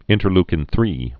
(ĭntər-lkĭn-thrē)